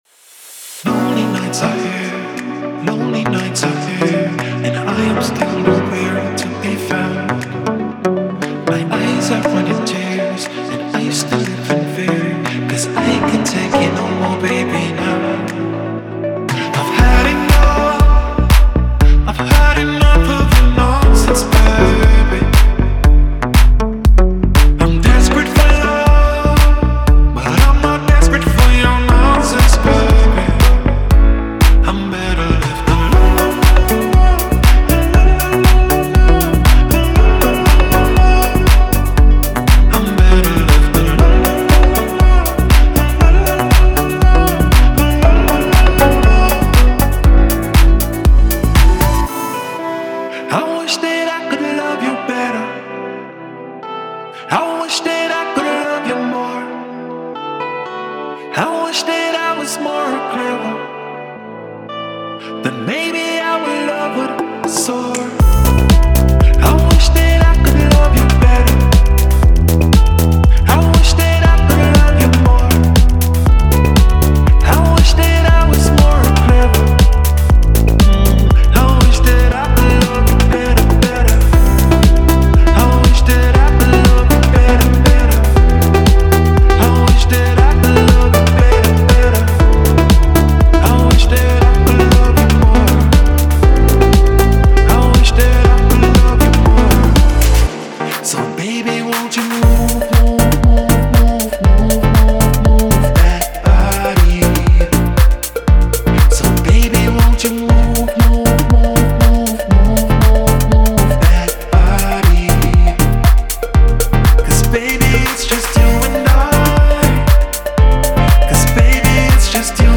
Genre:Pop
デモサウンドはコチラ↓
119 BPM